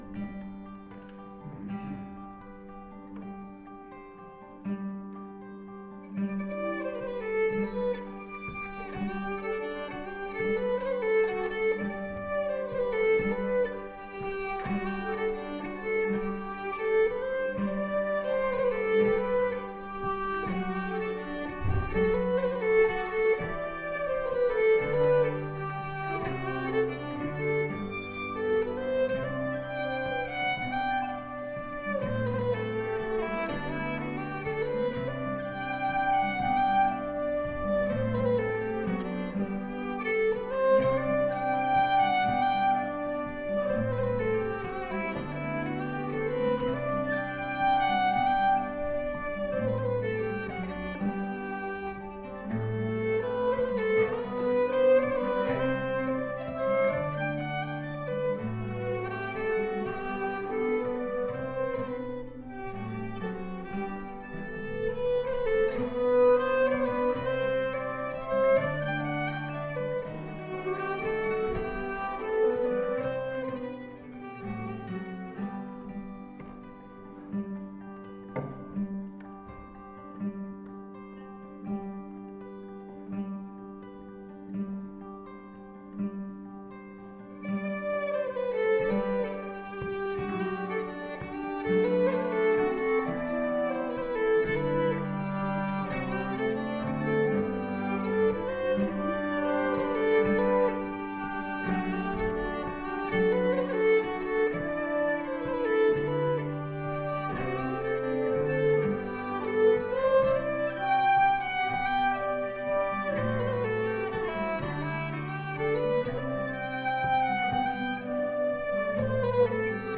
Guldkatt Svensk folkmusik med irländsk touch Februari 2013.
Flottsund June 2011